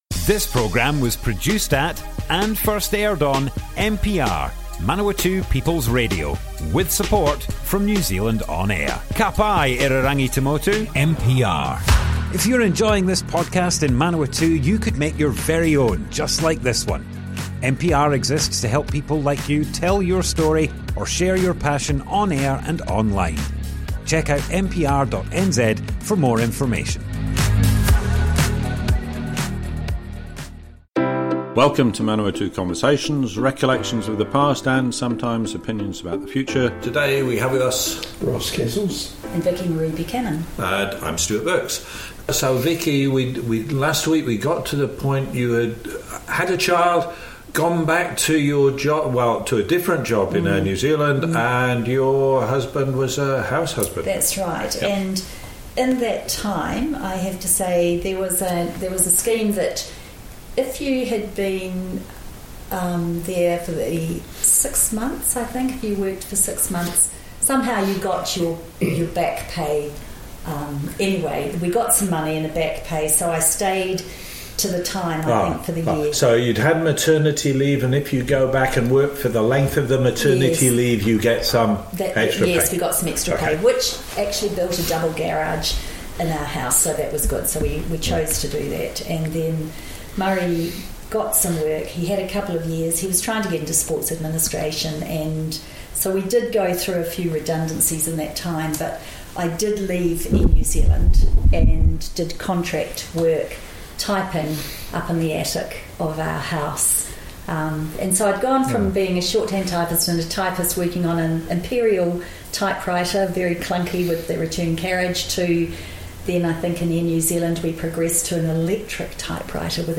Manawatu Conversations More Info → Description Broadcast on Manawatu People's Radio, 25th June 2024.
oral history